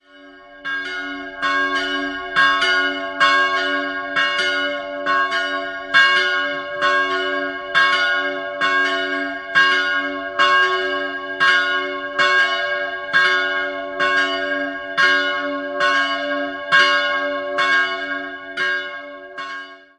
Jahrhundert wurde der Turm umgebaut, auch die Ausstattung der Kirche stammt im Wesentlichen aus der Barockzeit. 2-stimmiges Geläute: cis''-fis'' (tief) Die große Glocke wurde 1932 von Hamm (Regensburg) gegossen, die kleinere im Jahr 1580 von Caspar Dietrich.